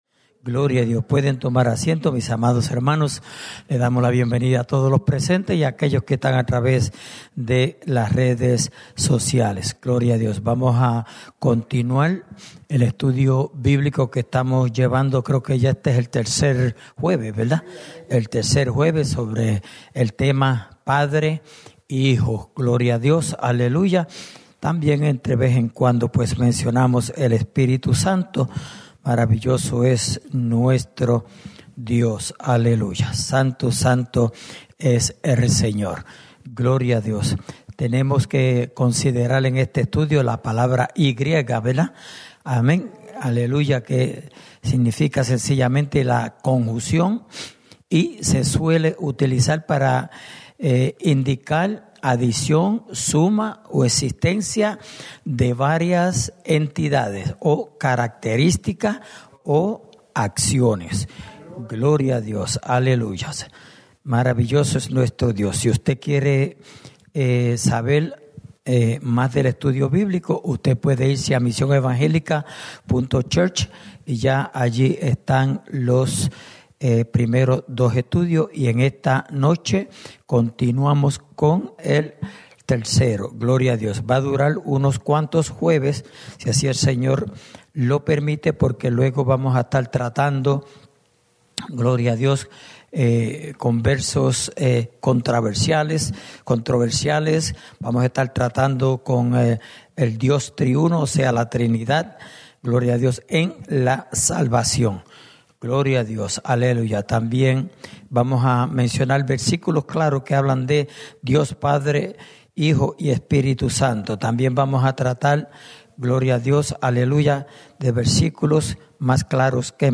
Estudio Bíblico: Padre Y Hijo (Tercera Parte)